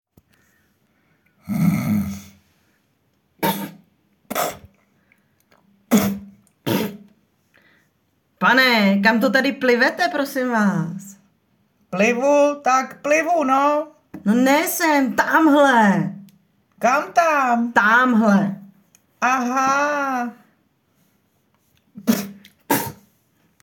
podivne_zvuky_na_Prasine.mp3